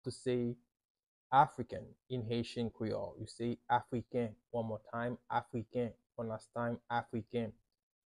How to say “African” in Haitian Creole – “Afriken” pronunciation by a native Haitian teacher
“Afriken” Pronunciation in Haitian Creole by a native Haitian can be heard in the audio here or in the video below: